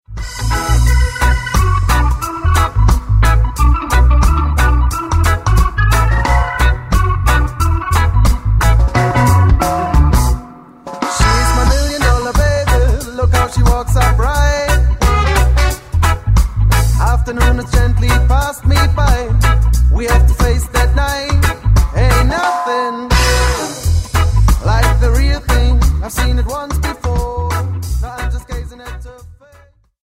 hinzu kommt ein Mix aus zwei Vocals.